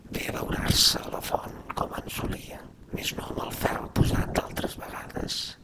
speech-male_hpsModel_stochastic
catalan hps hpsModel male residual sms sms-tools speech sound effect free sound royalty free Memes